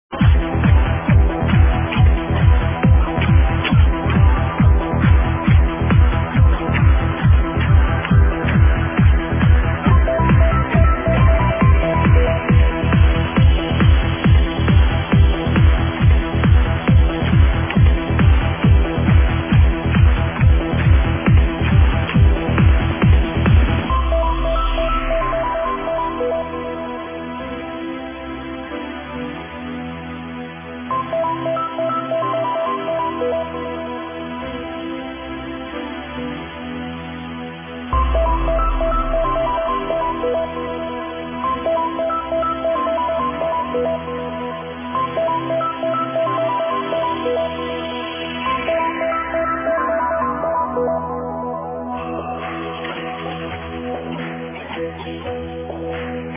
huge epic trance tune